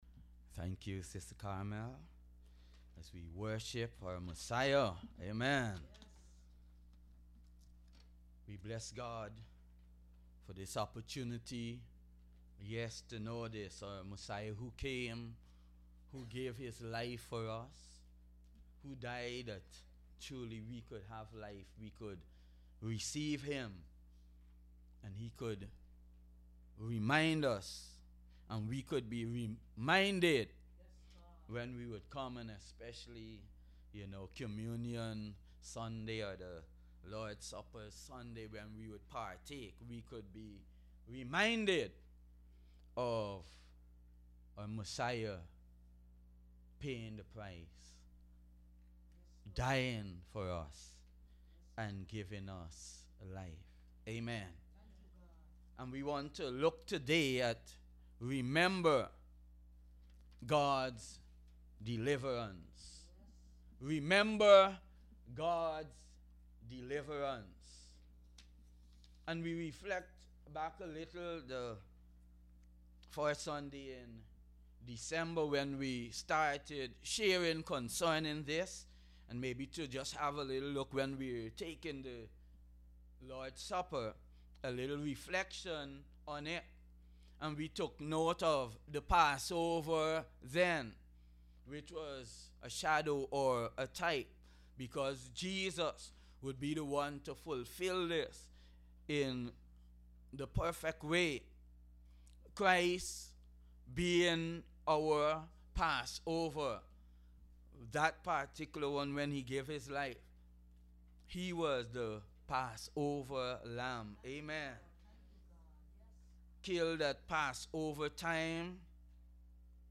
Audio Sermon Archive